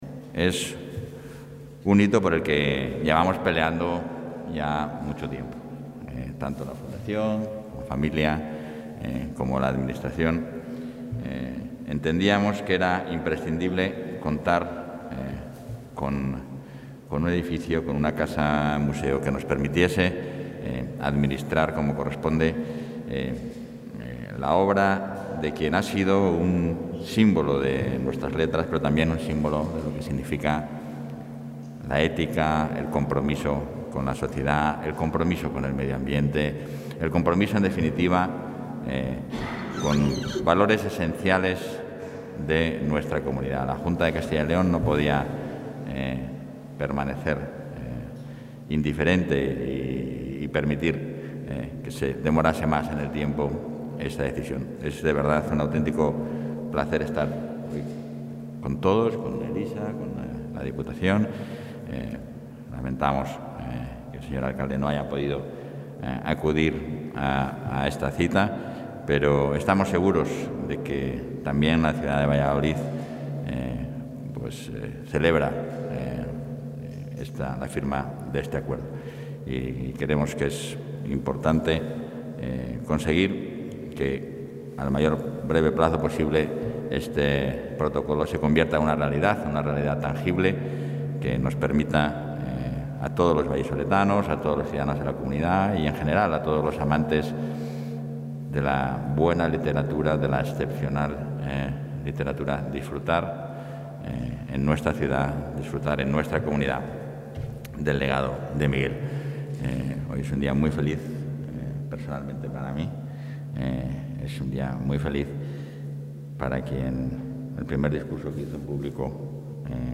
Valoración del vicepresidente.